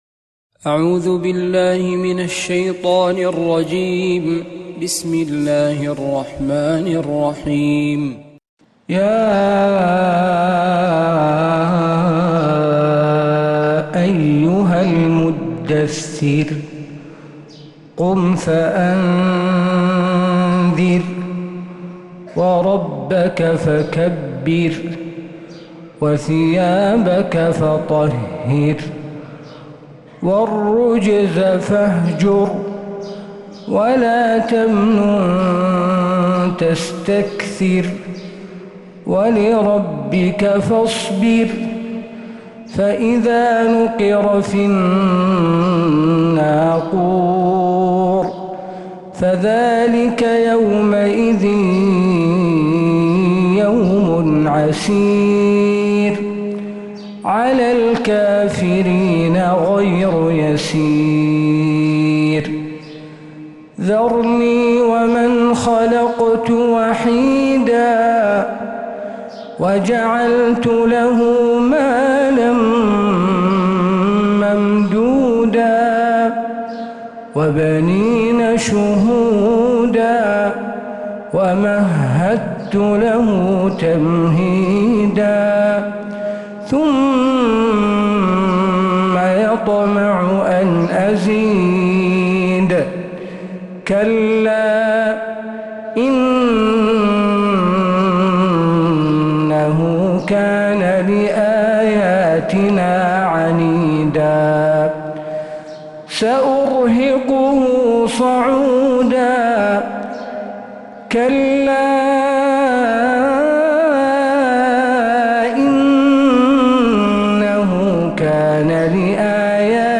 سورة المدثركاملة من فجريات الحرم النبوي